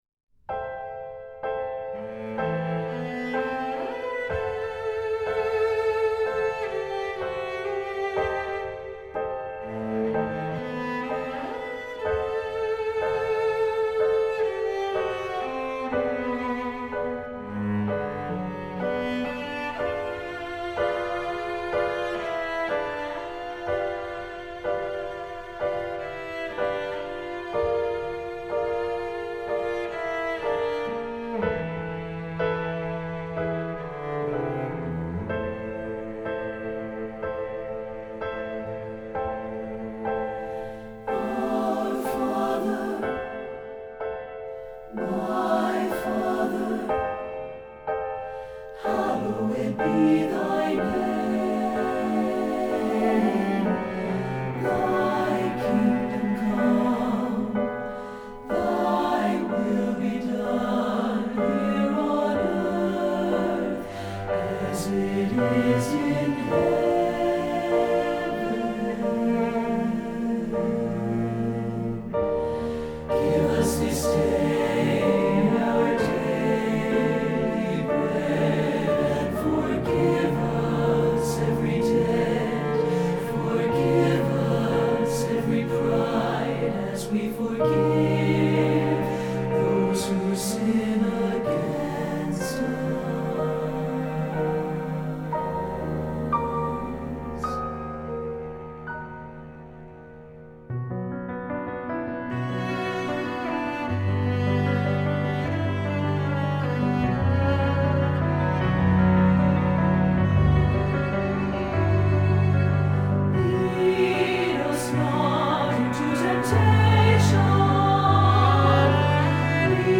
Choral Church
This anthem represents a very unique sonic experience.
SATB